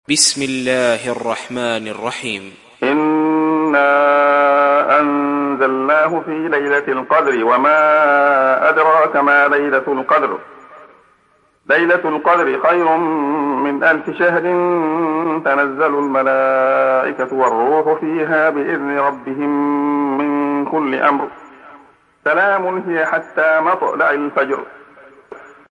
دانلود سوره القدر mp3 عبد الله خياط روایت حفص از عاصم, قرآن را دانلود کنید و گوش کن mp3 ، لینک مستقیم کامل